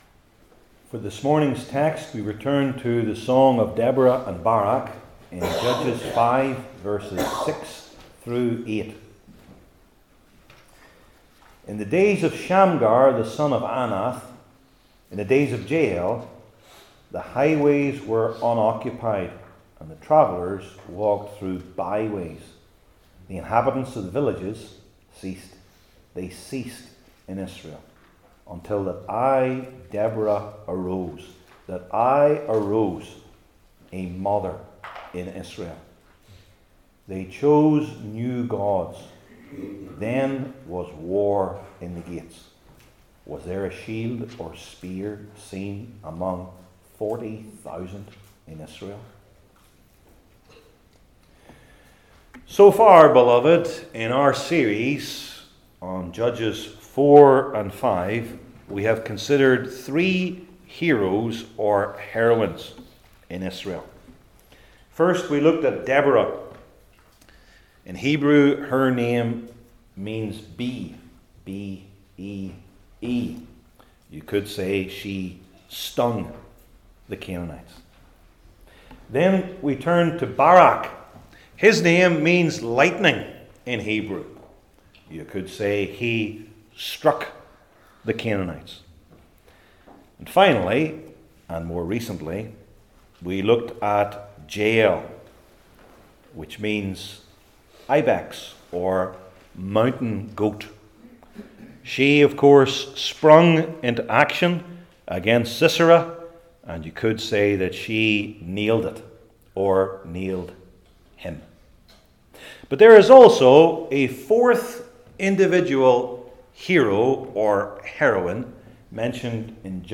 Old Testament Sermon Series I. The Nature of It II.